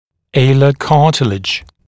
[‘eɪlə ‘kɑːtɪlɪʤ][‘эйлэ ‘ка:тилидж]хрящ крыла носа